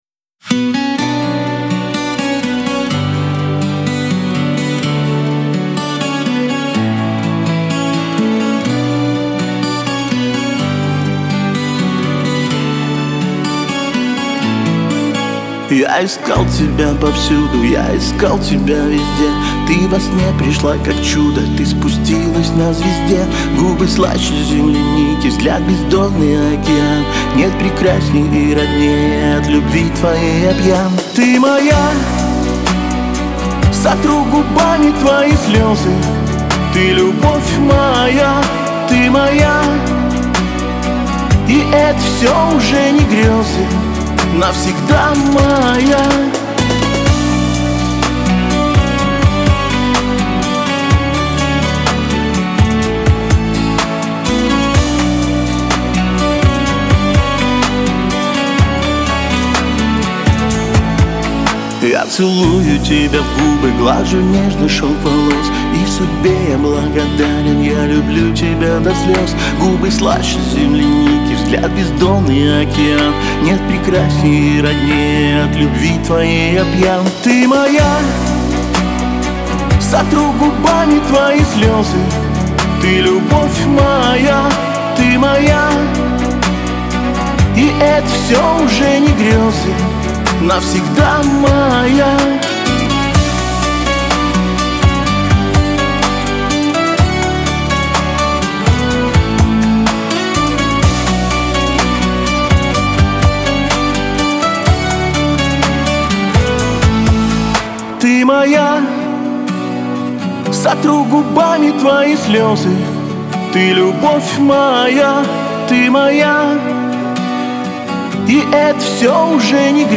Веселая музыка , эстрада
pop